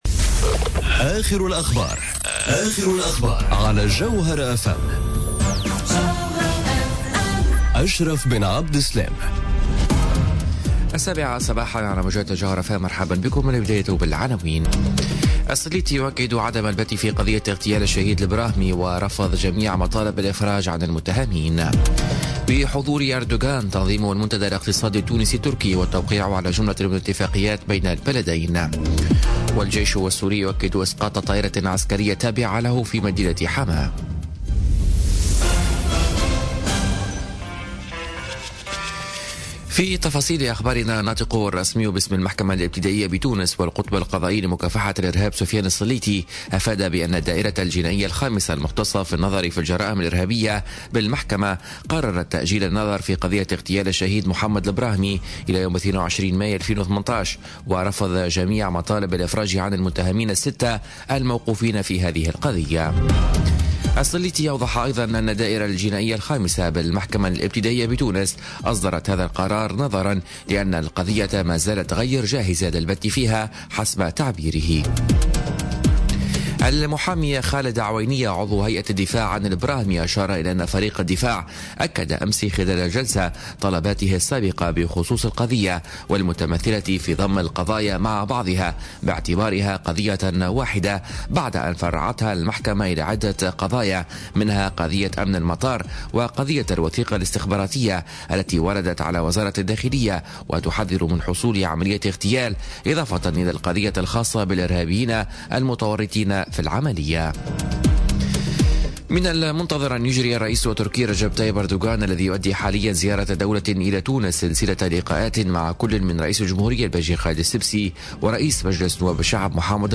نشرة أخبار السابعة صباحا ليوم الإربعاء 27 ديسمبر 2017